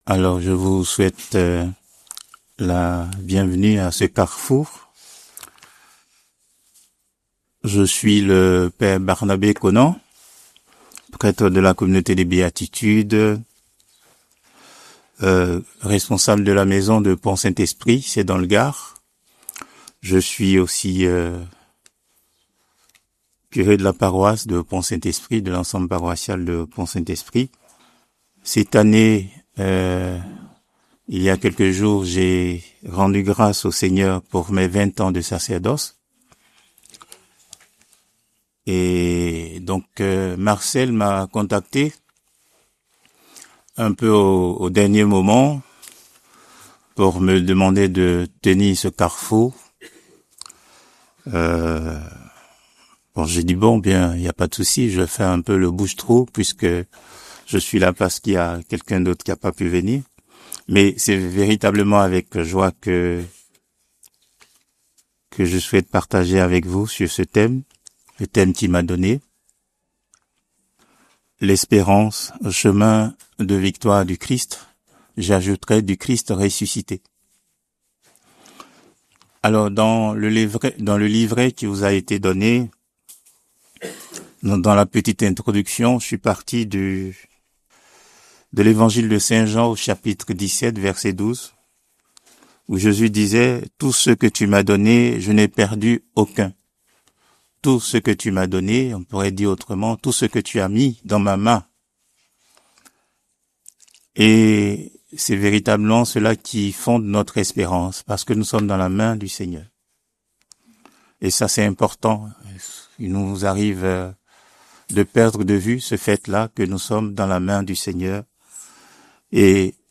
Lourdes, Pèlerinage avec la Cté des Béatitudes